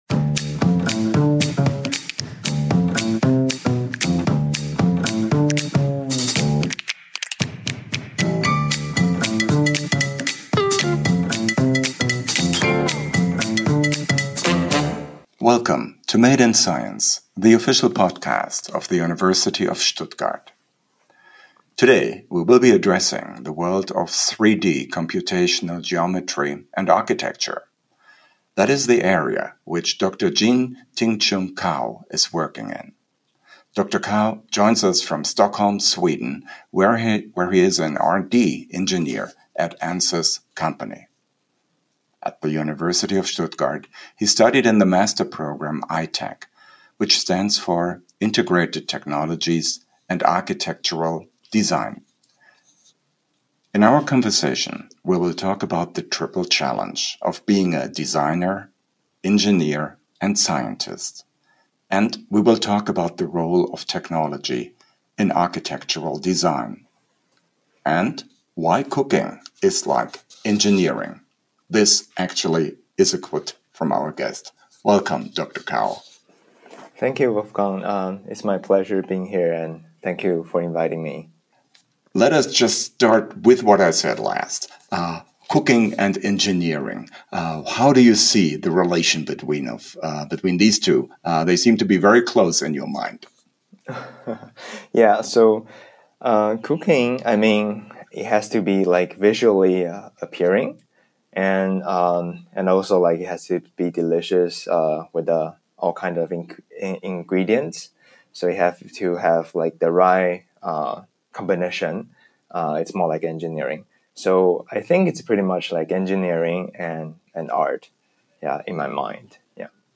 About ITECH In our conversation, we talked about the triple challenge of being a designer, engineer and scientist, about the role of technology in architectural design and why cooking is like engineering.